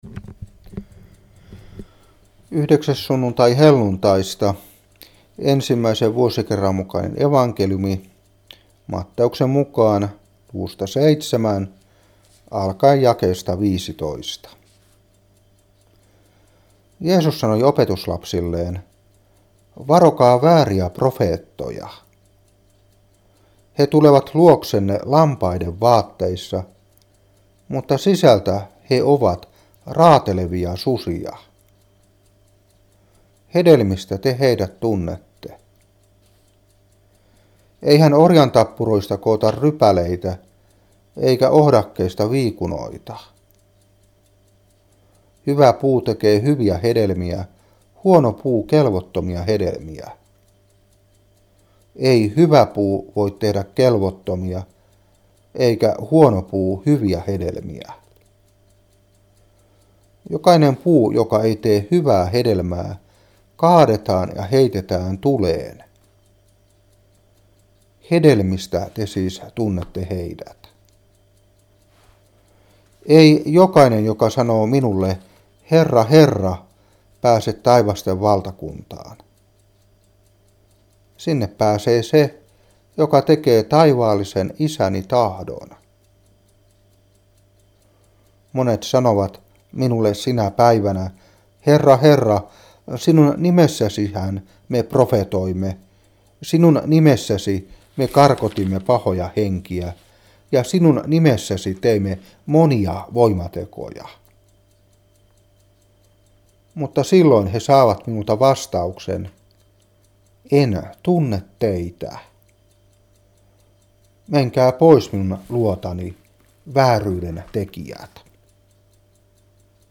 Saarna 2013-7.